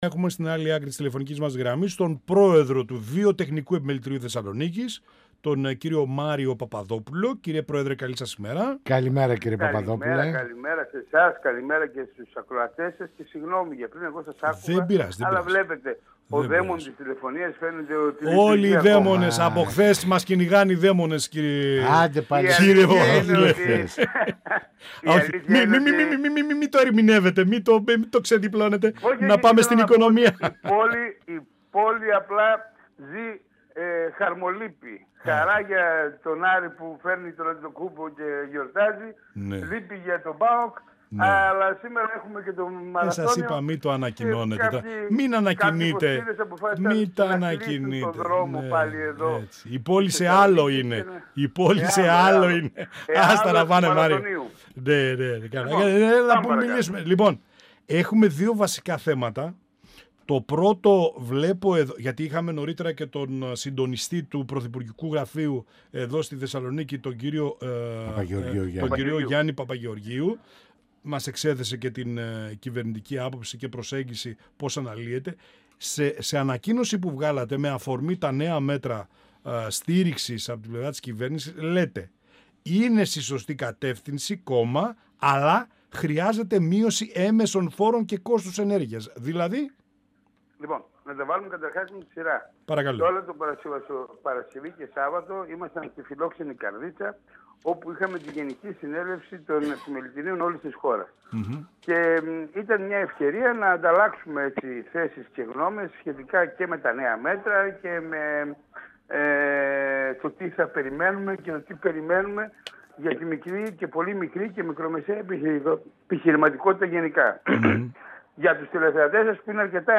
Πανοραμα Επικαιροτητας Συνεντεύξεις